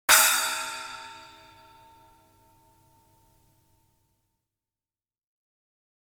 SABIAN（セイビアン）のHHシリーズ、8インチスプラッシュシンバル。薄くて小さいエフェクトシンバル。暗めの音色とすばやい音の立ち上がりが特徴です。